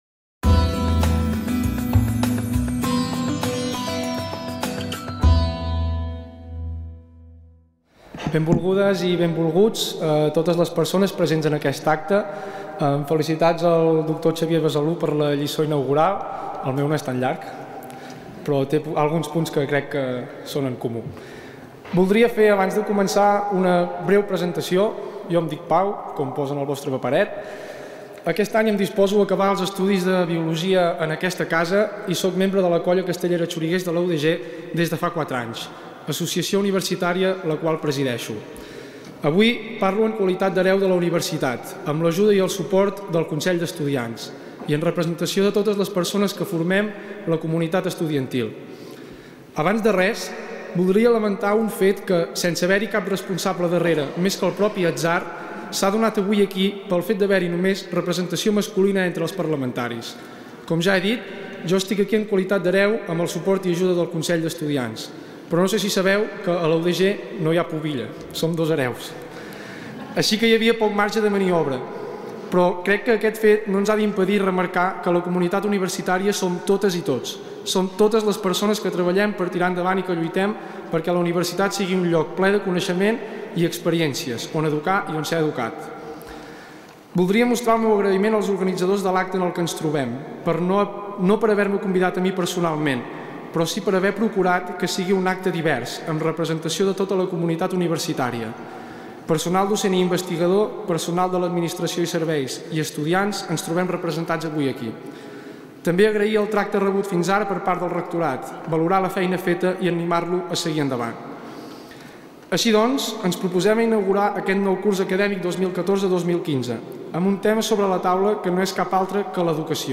Inauguració del curs 2014-2015. Parlament